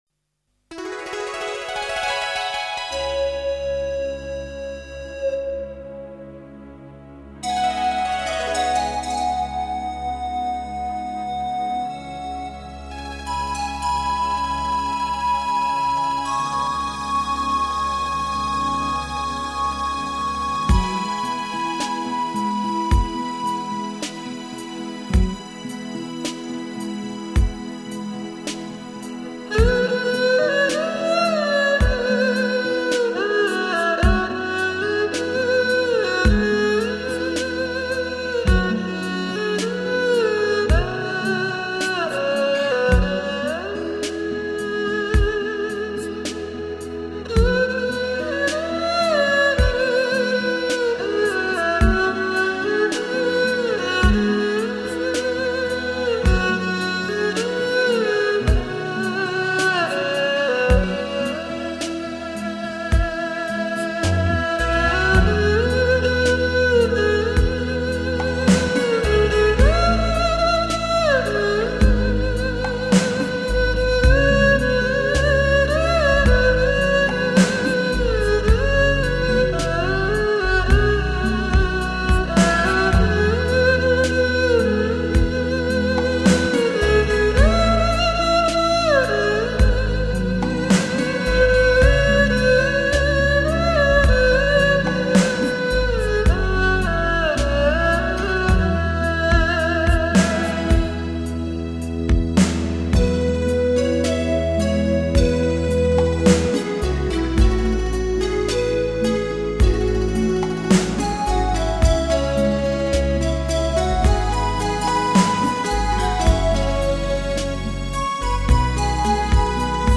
民族传统乐器演绎流行金曲，由音乐而生情，心灵仿佛经过了净化洗礼，感悟了人生的许多……
二胡，以一个全新的姿态，演绎名人名曲，在耳边轻声诉说心情故事，百听不厌……